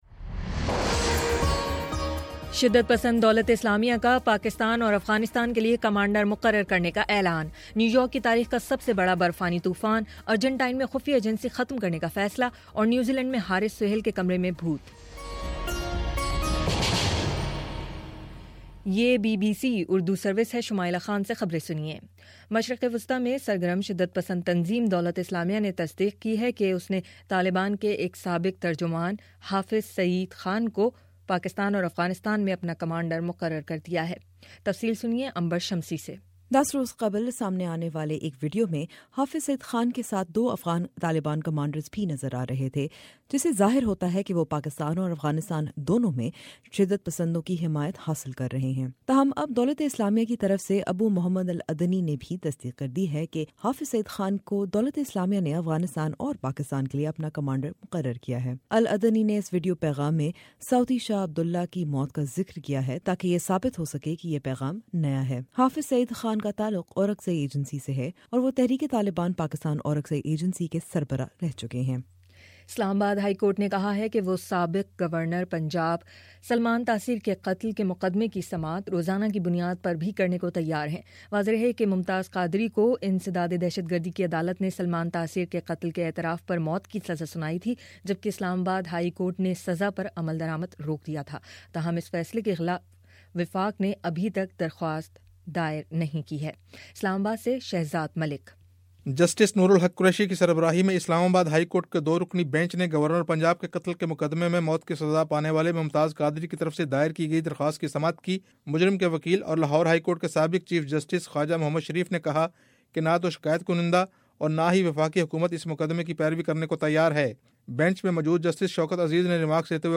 جنوری 27: شام چھ بجے کا نیوز بُلیٹن